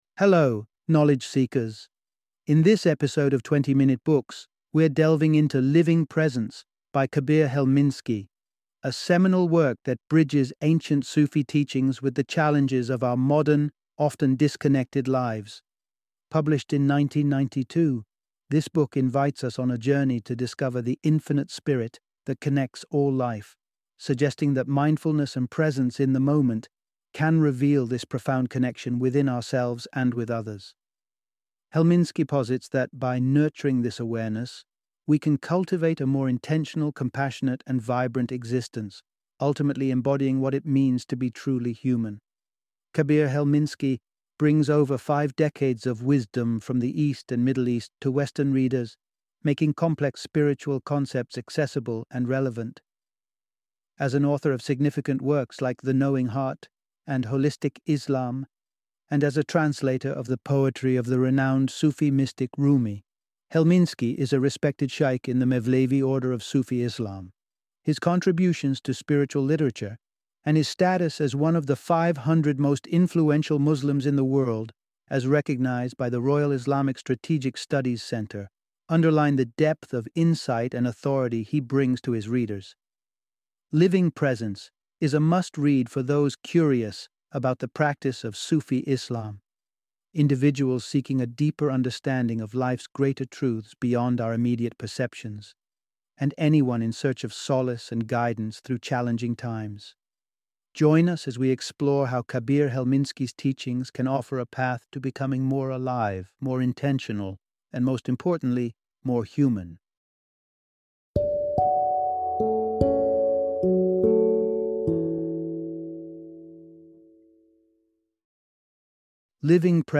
Living Presence - Book Summary